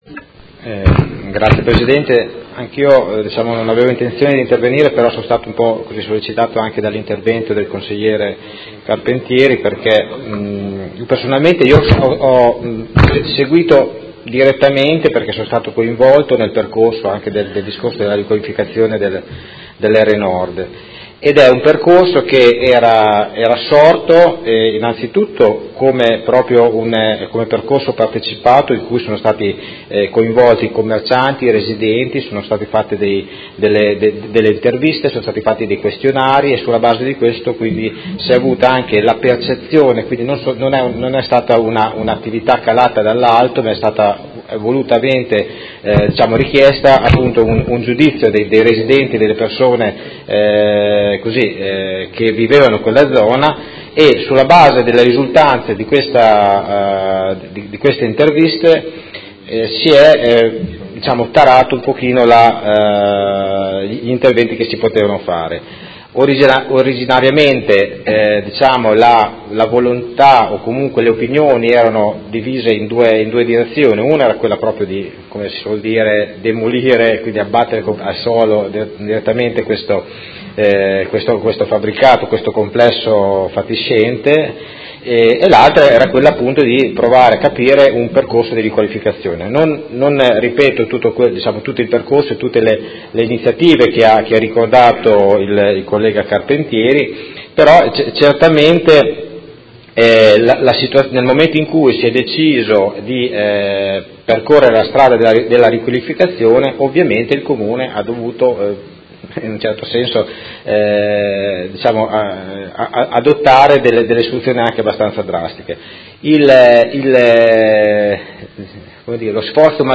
Vincenzo Walter Stella — Sito Audio Consiglio Comunale
Seduta del 23/11/2017 Dibattito.